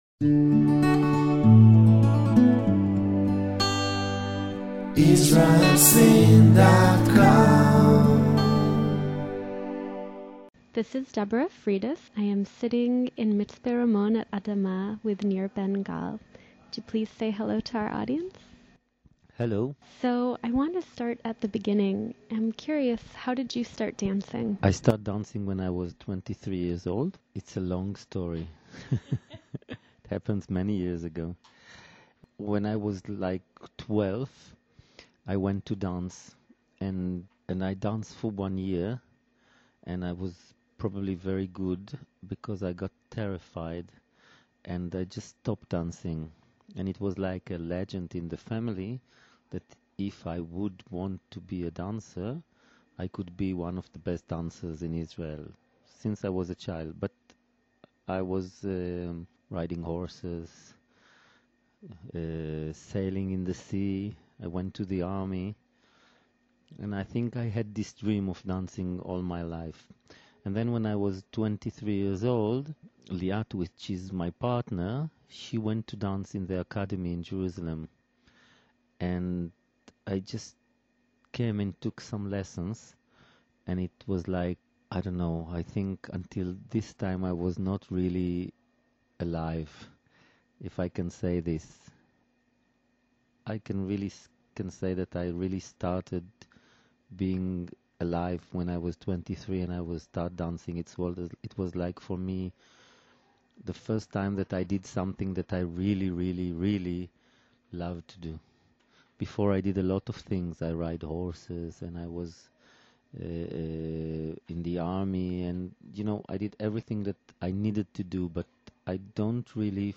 It was an inspiring conversation that continues to surface in my thoughts even outside of my research.